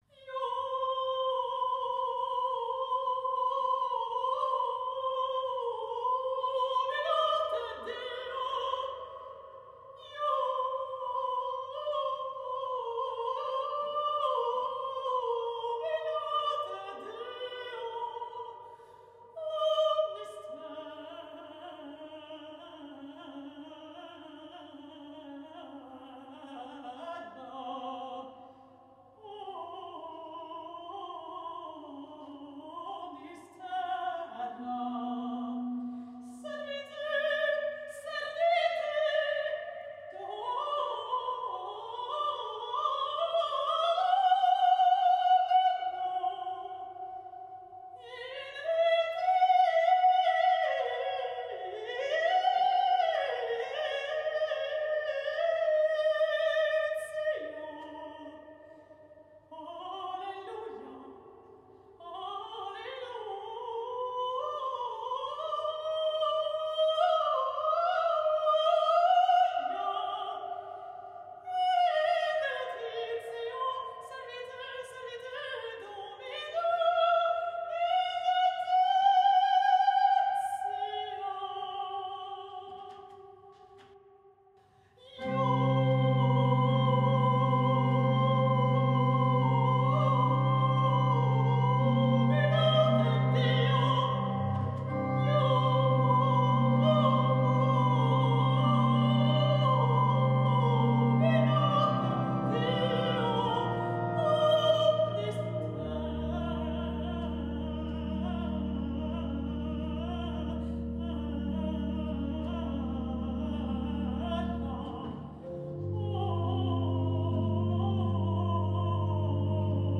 Jubilate Deo für Sopransolo a capella / Sopran & Orgel
Es lebt von ständigen Sechzehntelbewegungen, die den Text sehr einfach ausdeuten.
Hier können Sie einen Mitschnitt der Urraufführung (eine Kombination aus der Soloversion und der Version mit Orgel) herunterladen (mp3-Format, 3,52 MB)